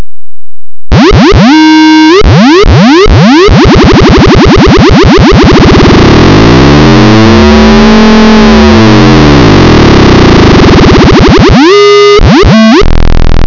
In the script, I've set up and wired virtual analog modules which successfully produced sounds in real-time.
here, but be warned that IT'S VERY LOUD!